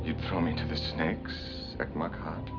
At 26:34 on the DVD, it sounds like Lt. James speaks some Visitorese, saying, "You'd throw me to the snakes? Luk mak ha?"